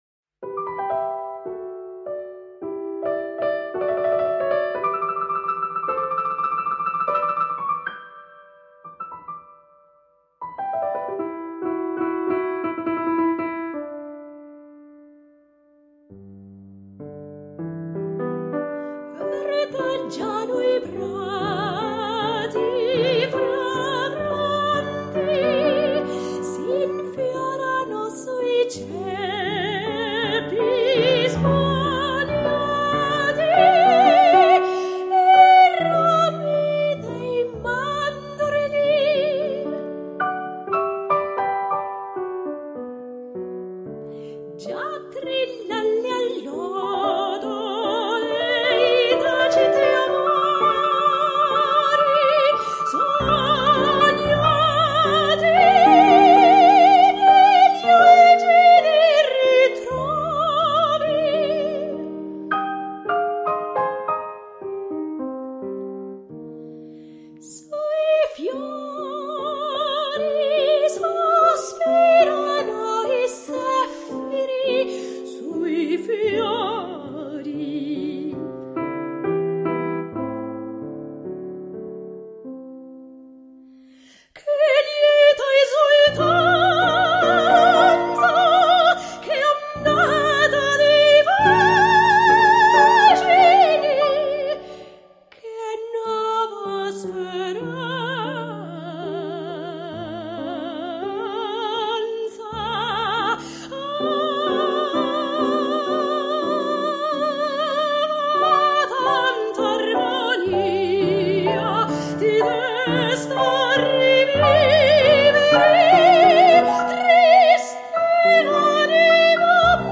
pianoforte.